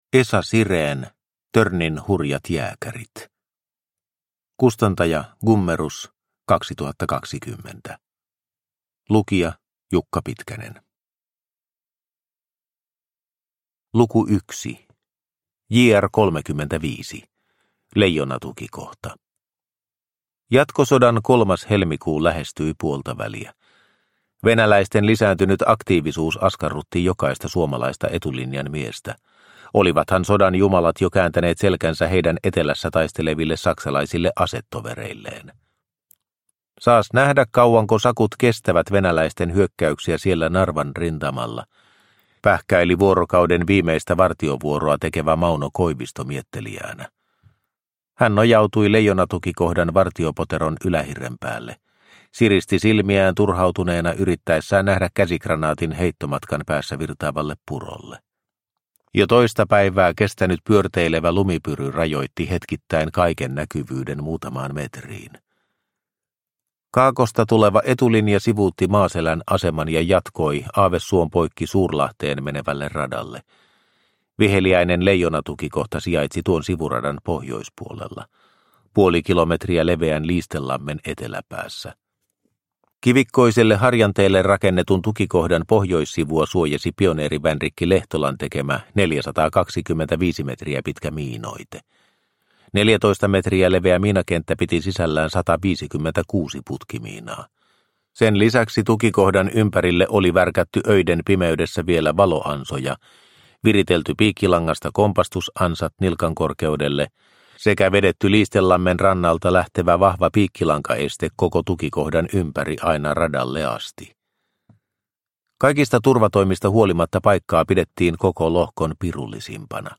Törnin hurjat jääkärit – Ljudbok – Laddas ner